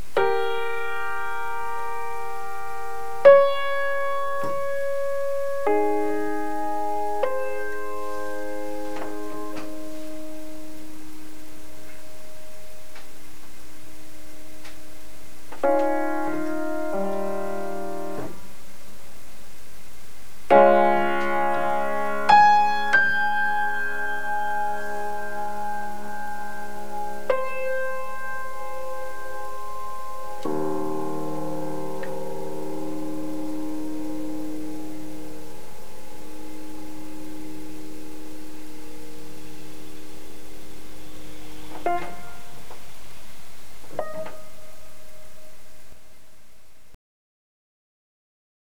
the pianist starts and ends the piece***